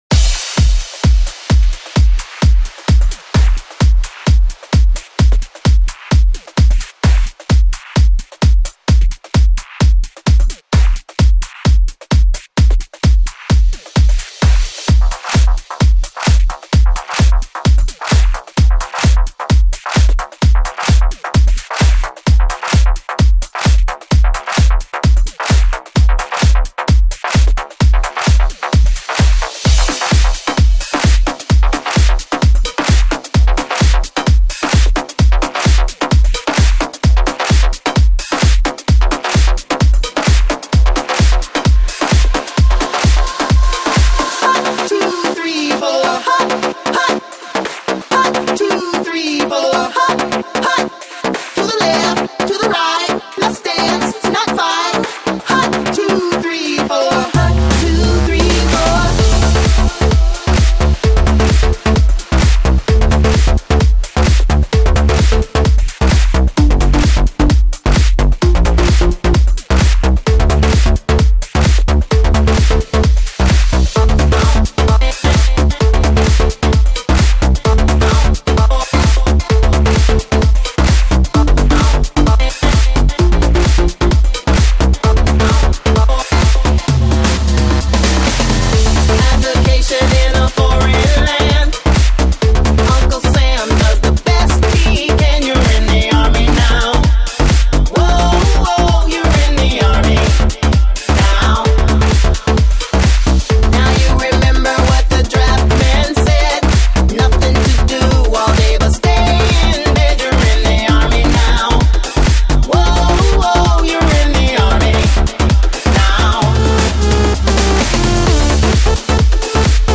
Потрясный позитивный трек!
Стиль: Electro House